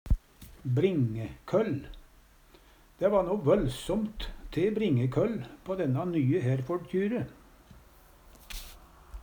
bringekøll - Numedalsmål (en-US)